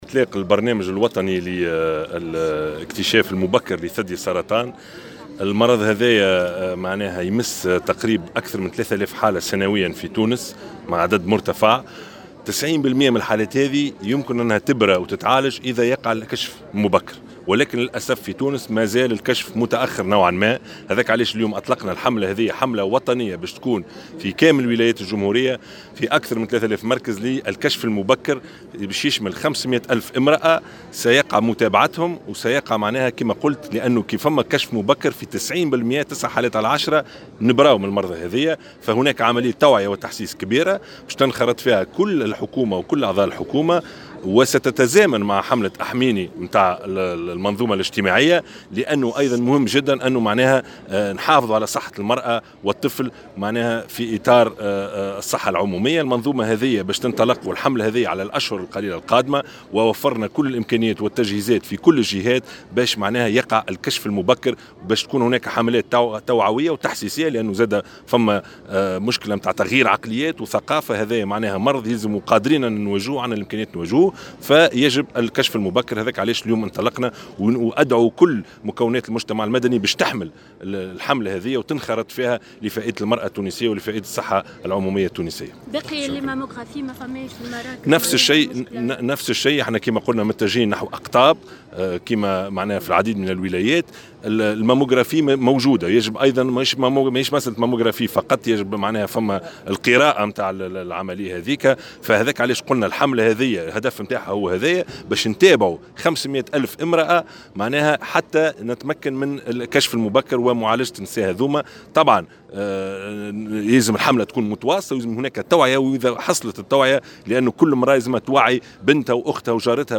وجاءت هذه التصريحات على هامش اعطاء اشارة انطلاق الحملة الوطنية في منوبة للكشف المبكرعن سرطان الثدي تحت شعار "تفقد روحك".